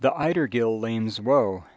Ider/outer, [aI] diphthong is identifiable from strong rise of F2 at .35 seconds of first spectrogram.
gill/dill:  [g] identifiable from velar pinch (F2 and F3 mutually close) at .55 of first spectrogram.
woe/roe:  In first spectrogram, around 1.25 second, F2 and F3 fail to converge, indicating [w] rather than [r].
TheIderGillLamesWoe.wav